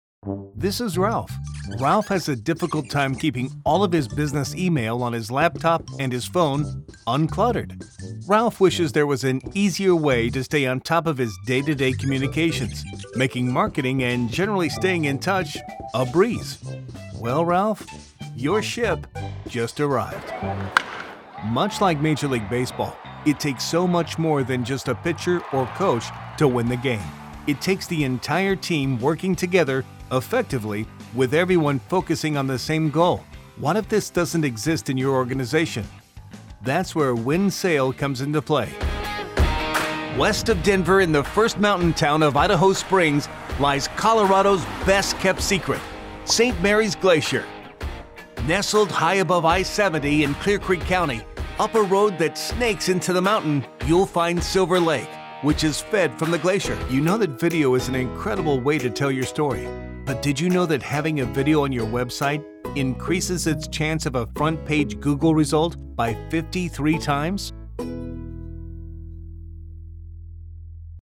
Comercial, Llamativo, Seguro
Explicador